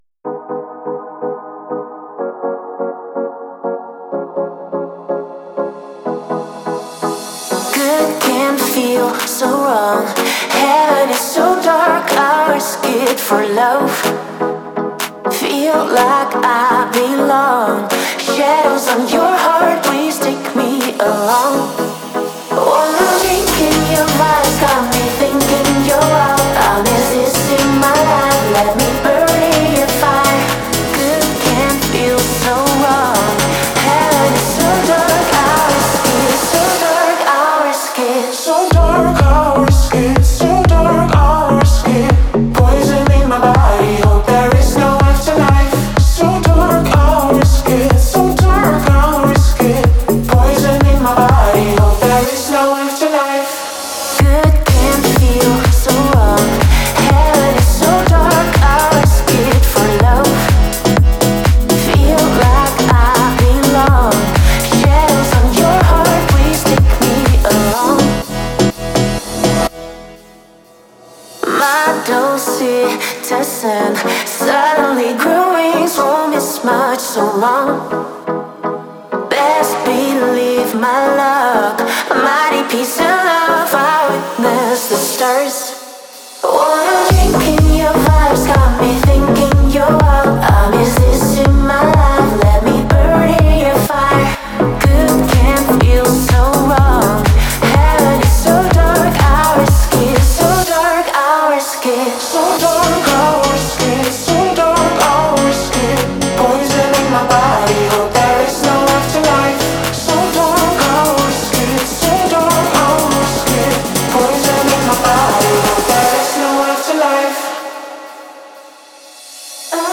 это атмосферная трек в жанре электронной музыки